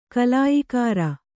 ♪ kalāyikāra